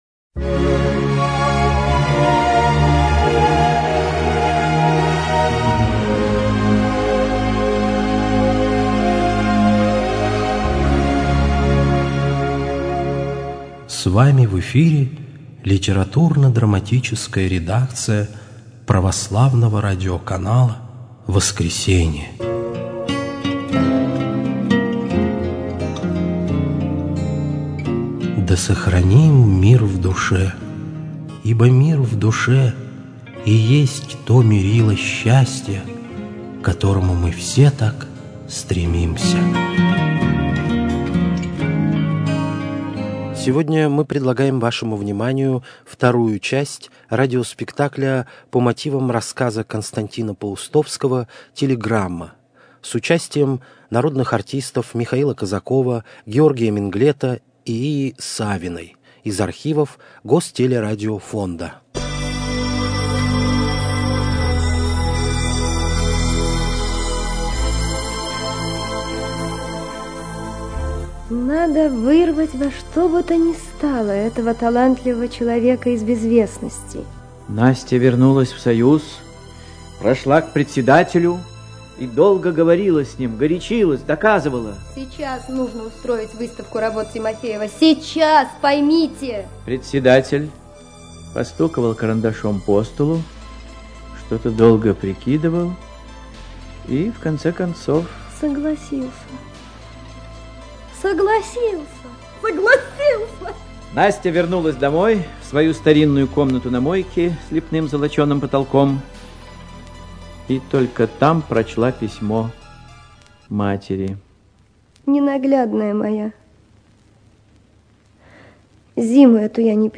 Радиоспектакль "Телеграмма". По рассказу К. Паустовского ч. 2
radiospektakl_telegramma_po_rasskazu_k_paustovskogo_ch_2.mp3